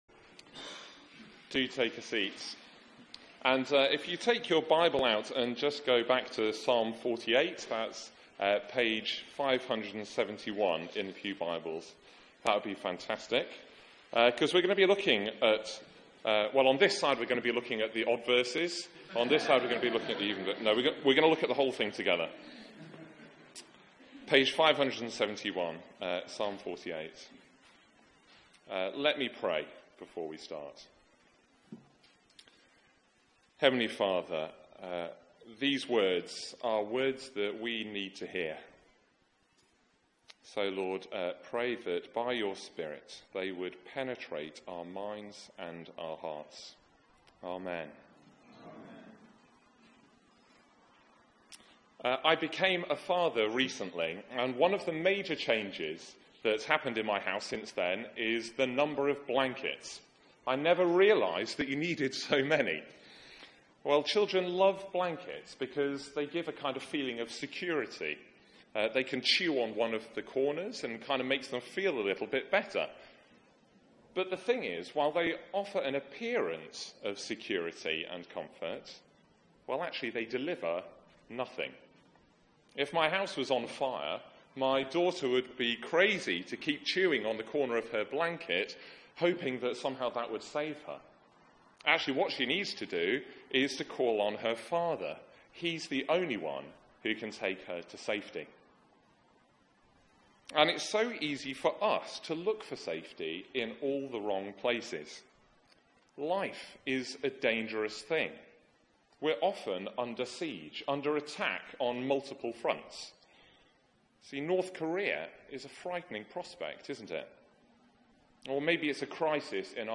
Media for 6:30pm Service on Sun 27th Aug 2017 18:30 Speaker
Series: Summer Psalms Theme: God's dwelling place Sermon Search the media library There are recordings here going back several years.